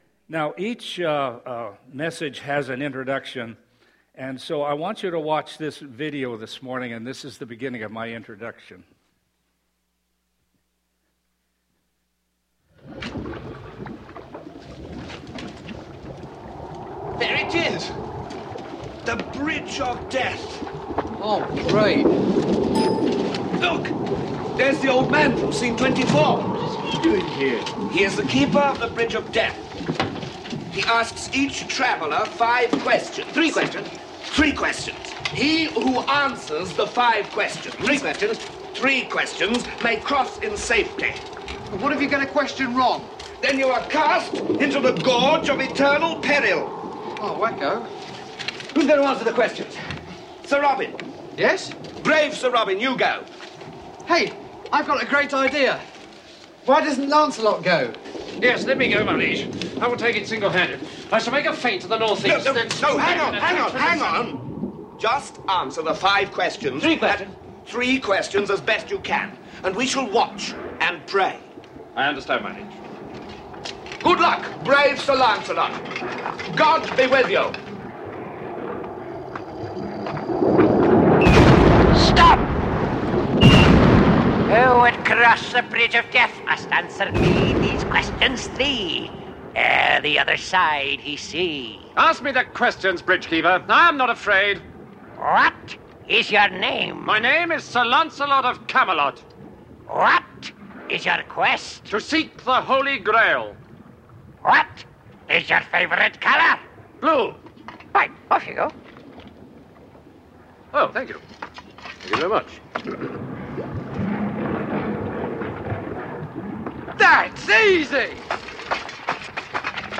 Sermons | Bethel Church Ladysmith
*The video shown at the beginning is a clip from Monty Python and the Holy Grail.